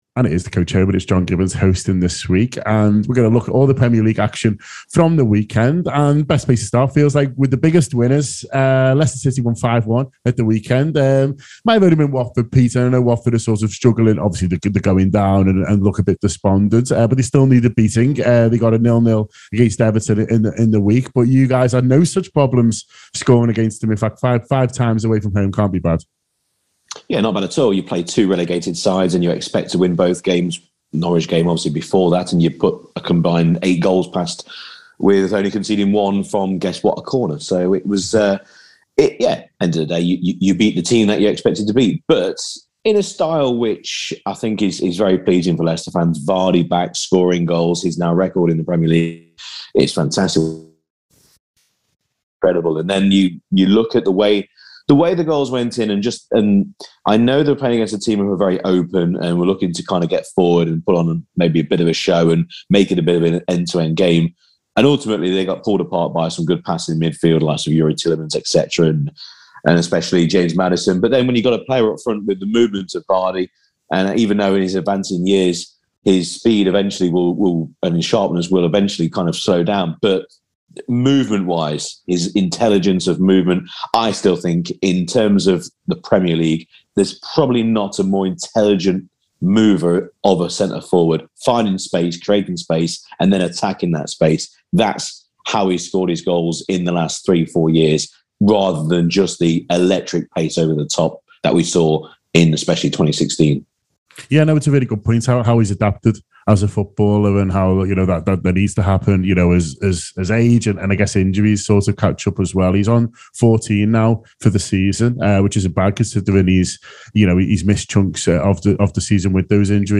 Fan reaction to the weekend’s Premier League results, including Leeds and Spurs pushing the relegation and top four races to the last day.